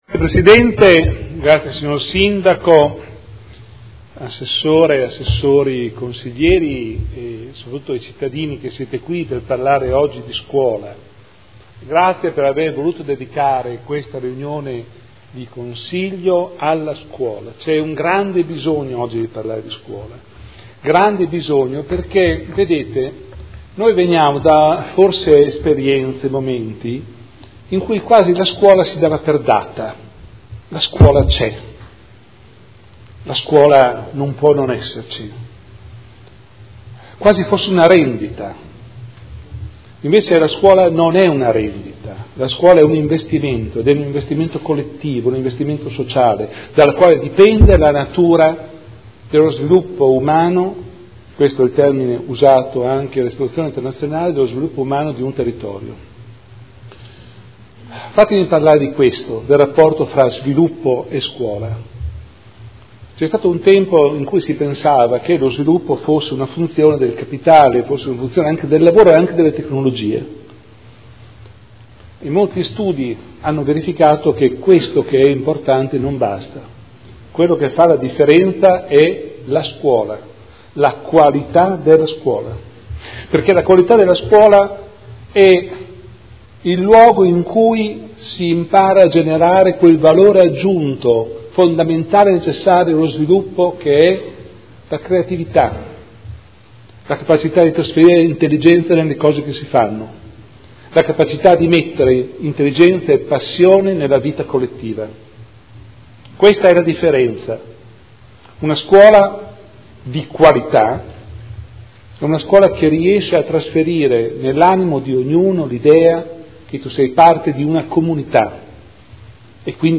Seduta del 17/09/2015.